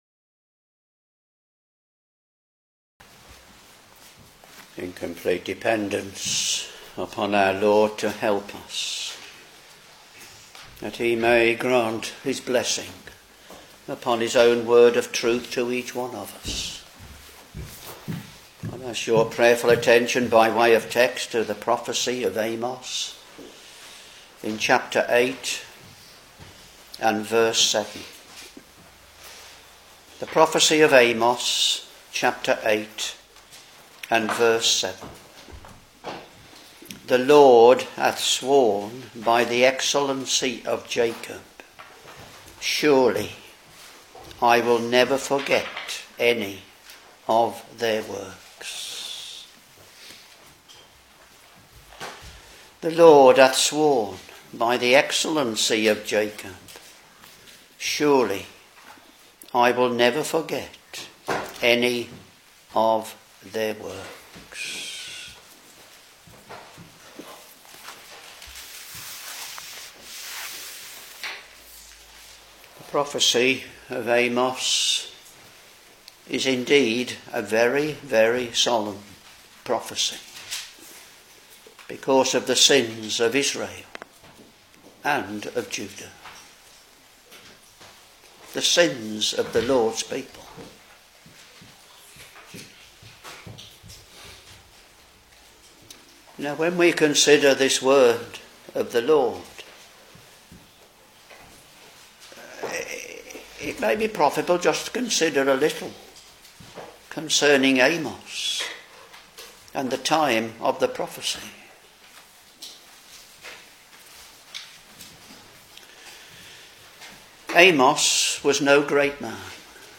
Sermons Amos Ch.8 v.7 The LORD hath sworn by the excellency of Jacob, Surely I will never forget any of their works.